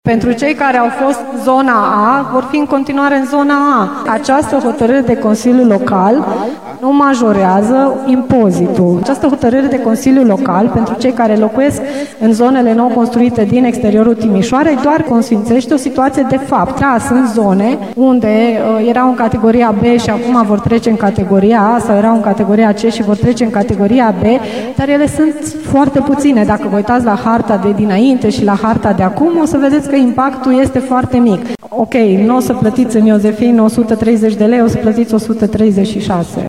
Viceprimarul Paula Romocean a declarat în plenul Consiliului Local că în unele cazuri vor exista majorări de impozite, dar impactul va fi foarte mic.